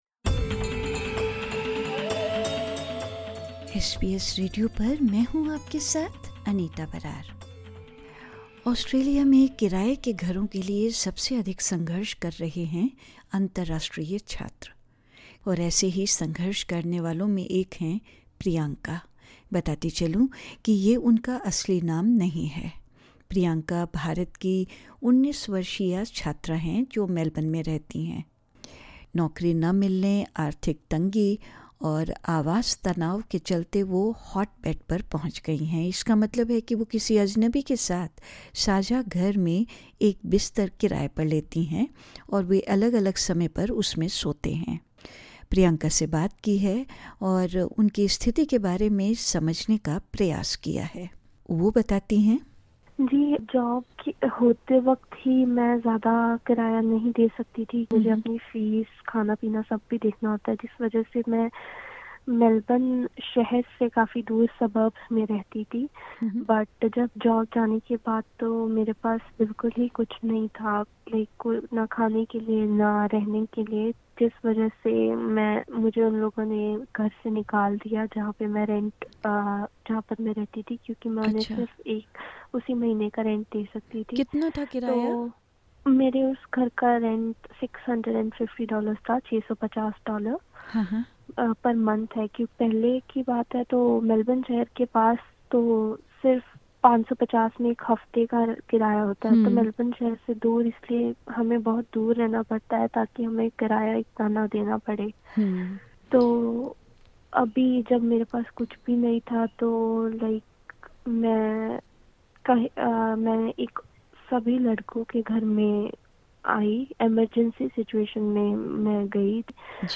Disclaimer: The views expressed in this interview are of the interviewee.